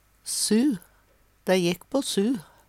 DIALEKTORD PÅ NORMERT NORSK su sør Eksempel på bruk Dei jekk på su.